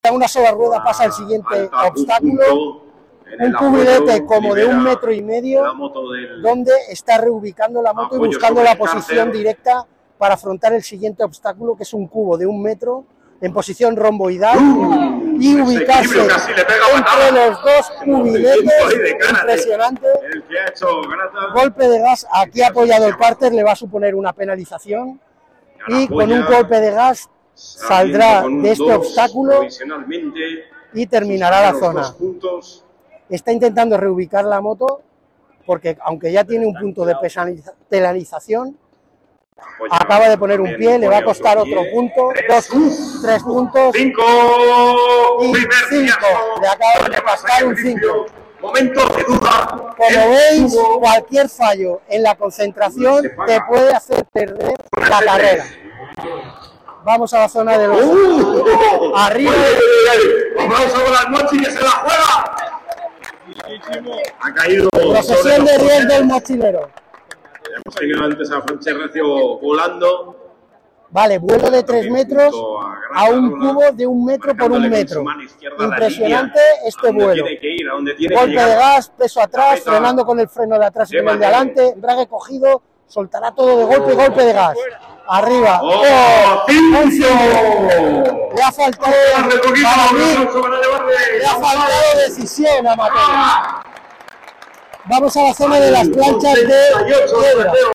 Llegado el momento de la competición, cada uno recibió un receptor con el que escucharían la detallada audiodescripción que les hizo meterse de lleno en el campeonato, experimentando todas las sensaciones y escuchando todos los sonidos del motor.